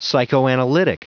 Prononciation du mot psychoanalytic en anglais (fichier audio)
Prononciation du mot : psychoanalytic